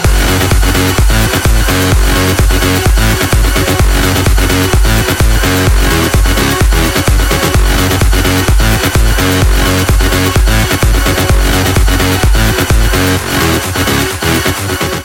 • Качество: 192, Stereo
Энергичный клубный трек в ретро стиле